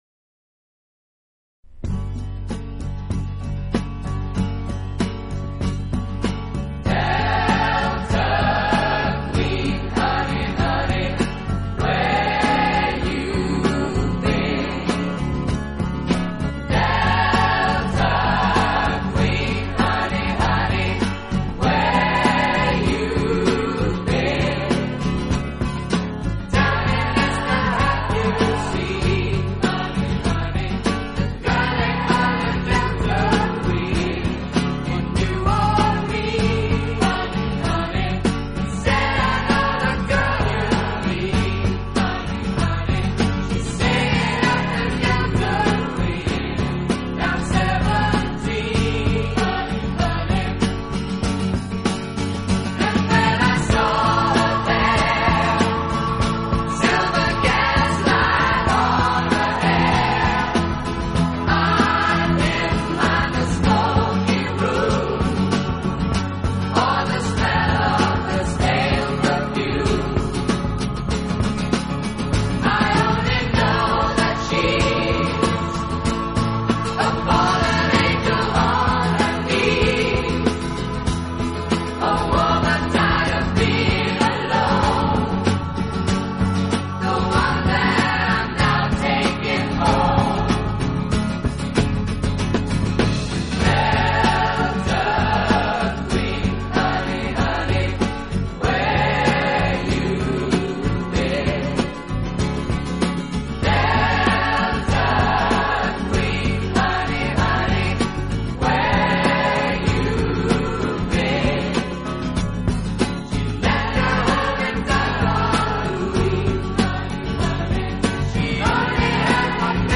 以热烈的旋律，独特的和声赢得千百万听众
此外，这个乐队还配置了一支训练有素，和声优美的伴唱合唱队。